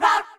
rahRahSisBoomBaBoomgirls1.ogg